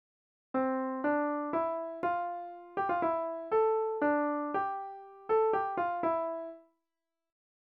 fugue-1-c-subject.mp3